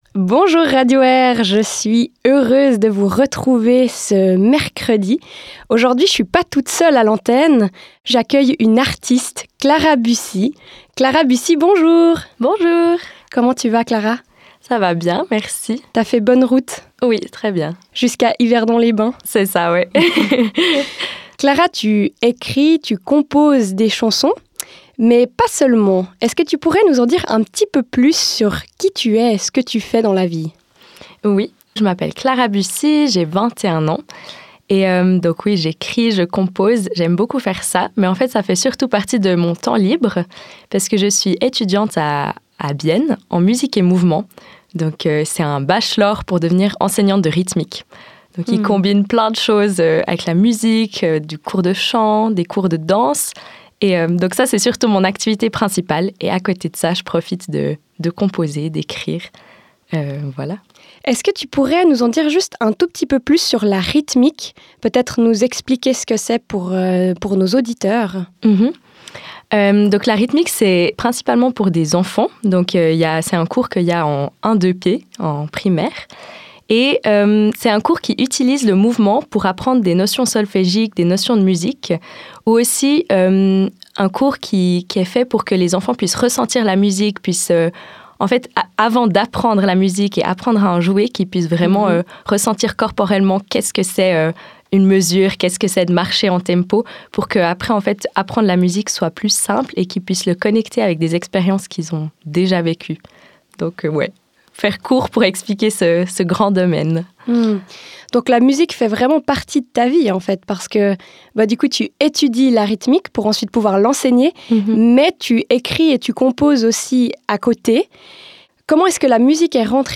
Dans cette courte interview